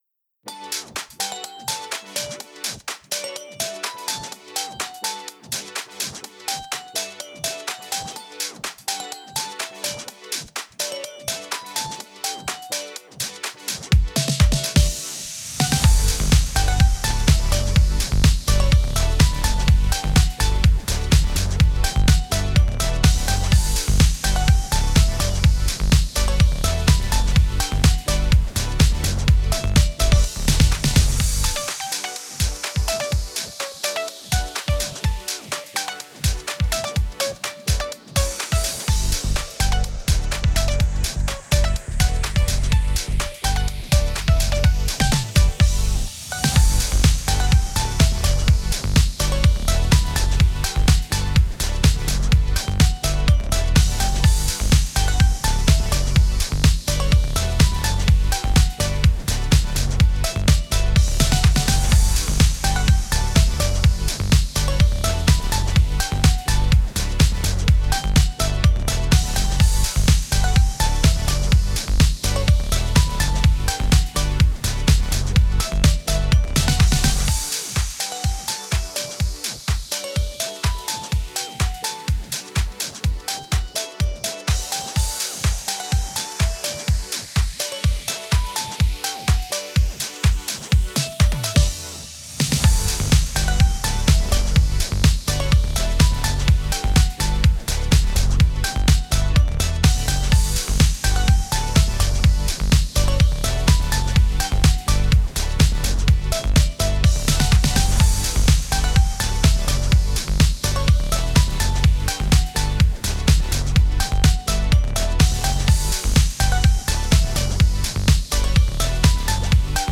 ハッピーな雰囲気の明るくかわいいゴキゲンBGMです◎ 「よっしゃあ！」